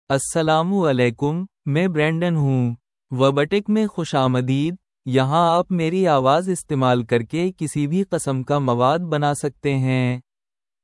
Brandon — Male Urdu (India) AI Voice | TTS, Voice Cloning & Video | Verbatik AI
BrandonMale Urdu AI voice
Brandon is a male AI voice for Urdu (India).
Voice sample
Male
Brandon delivers clear pronunciation with authentic India Urdu intonation, making your content sound professionally produced.